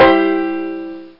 Banjo Maj Sound Effect
Download a high-quality banjo maj sound effect.
banjo-maj.mp3